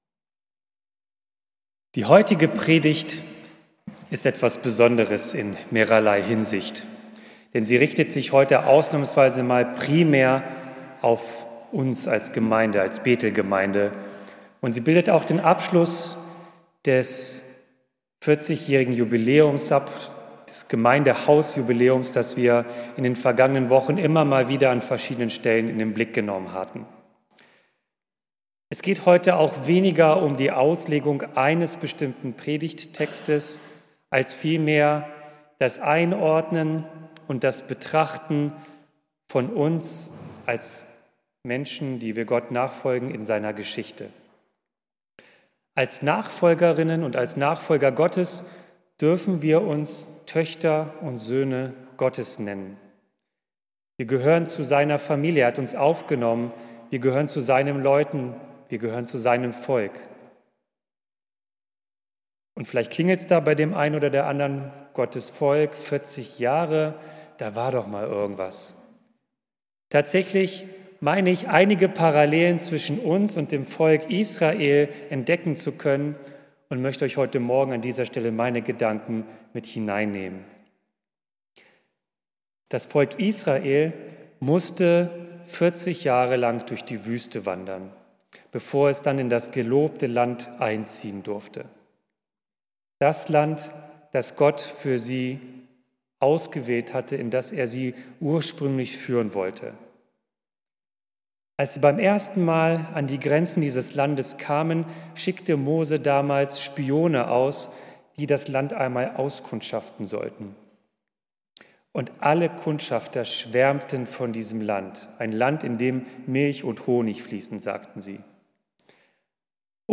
Predigt | Bethel-Gemeinde Berlin Friedrichshain